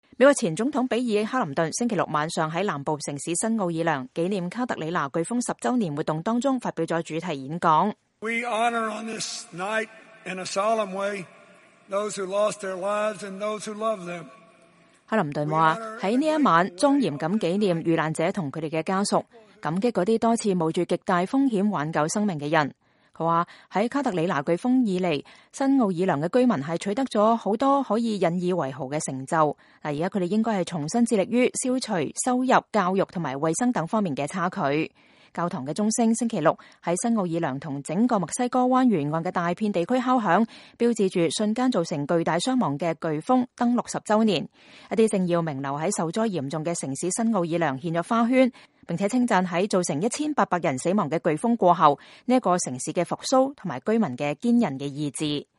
美國前總統比爾-克林頓星期六晚上在南部城市新奧爾良紀念卡特里娜颶風10週年活動中發表了主題演講。